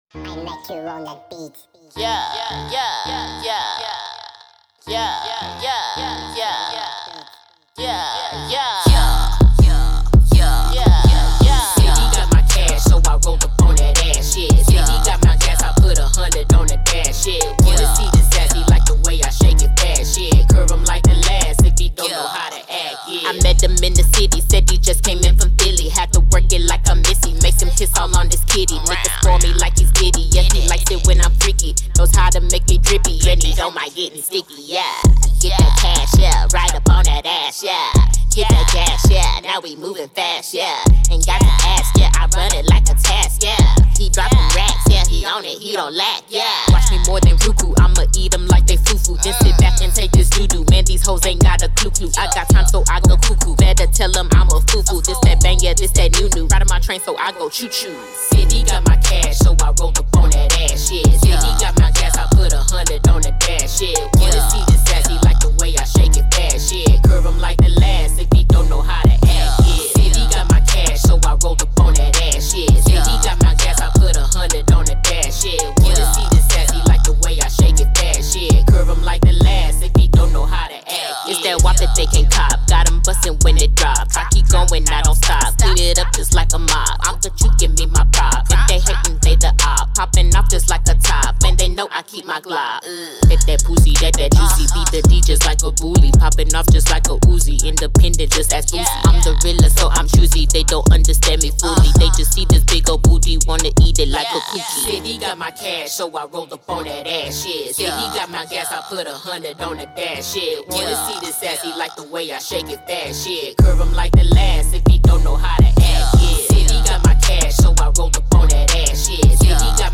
Hiphop
New Hot Club Banger!